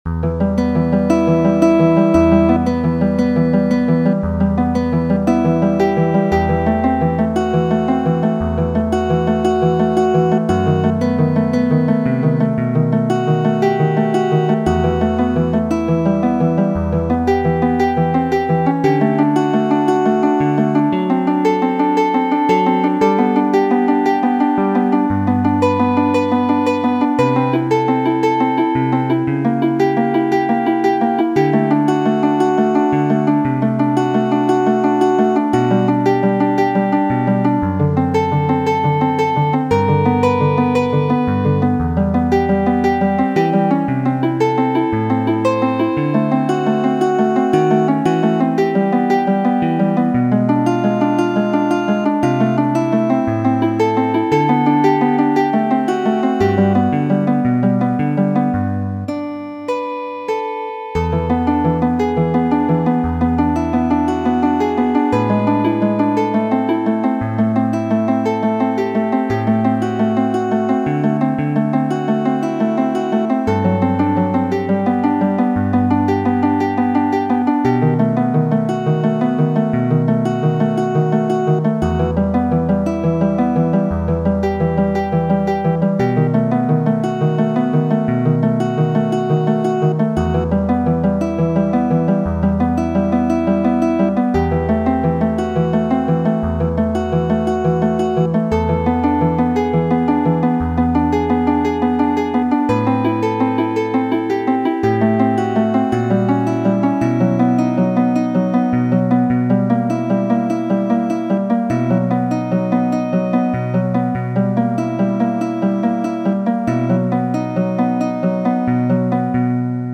Originala versio, ludita de Narciso Yepes, hispana gitaristo.